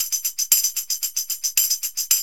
TAMB LP 114.wav